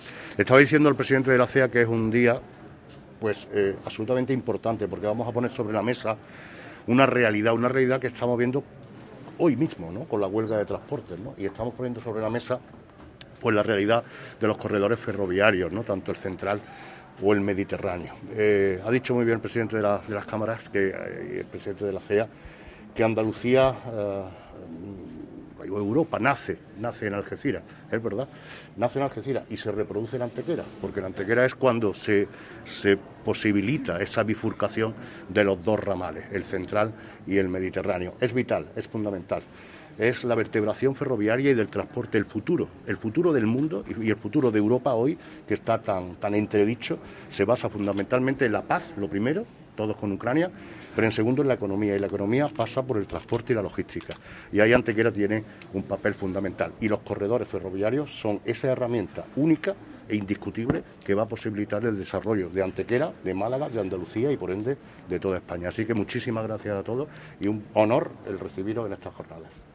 El alcalde de Antequera, Manolo Barón, participó tanto en la bienvenida como en la clausura de las jornadas, asegurando que se trataba de "un día absolutamente importante porque vamos a poner sobre la mesa una realidad que estamos viendo hoy mismo con la huelga de transporte y estamos poniendo sobre la mesa la realidad de los corredores ferroviarios, tanto central como mediterráneo".
Cortes de voz